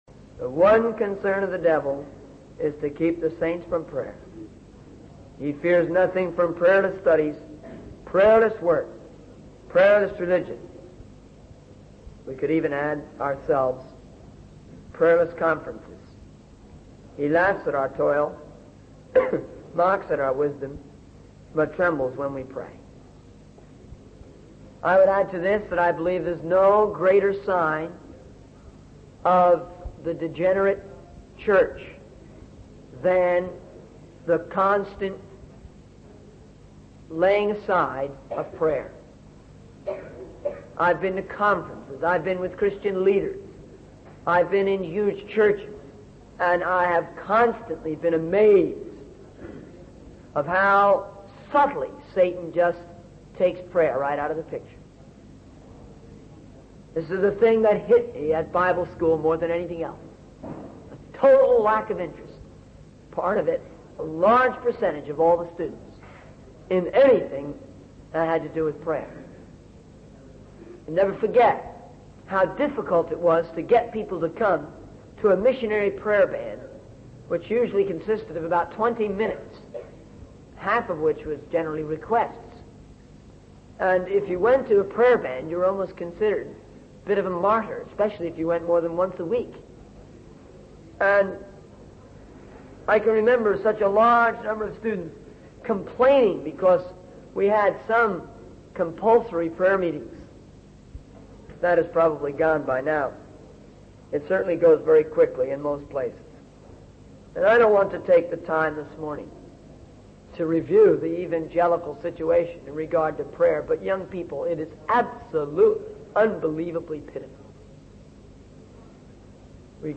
In this sermon, the preacher emphasizes the neglect of prayer in the Christian movement.